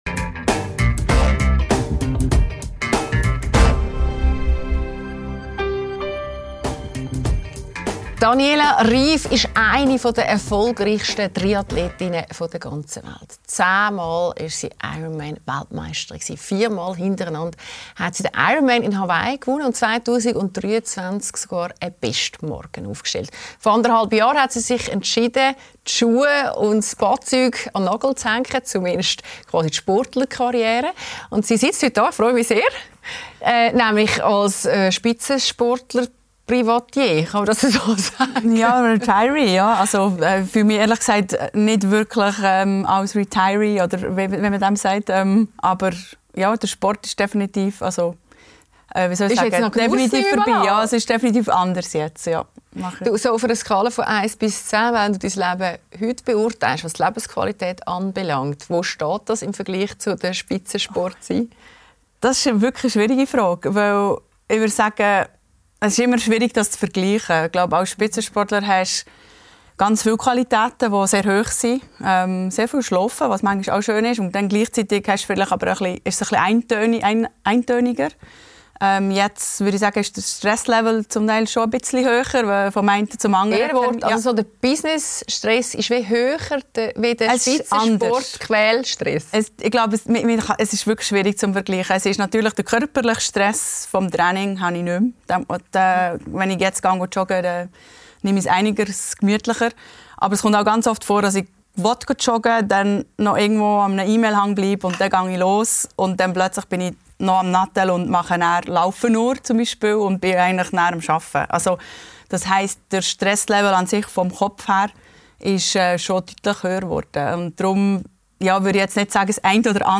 Daniela Ryf hat Triathlon-Geschichte geschrieben: Zehn WM-Titel, viermal in Folge auf Hawaii gewonnen. In der Talkshow «Lässer» spricht sie offen über persönliche Tiefpunkte, Essstörungen und warum im Spitzensport das Umfeld so wichtig ist.